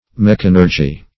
Meaning of mechanurgy. mechanurgy synonyms, pronunciation, spelling and more from Free Dictionary.
Search Result for " mechanurgy" : The Collaborative International Dictionary of English v.0.48: Mechanurgy \Mech"an*ur`gy\, n. [Gr. mhchanh` machine + the root of ? work.]
mechanurgy.mp3